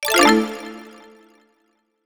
PictureMessage.wav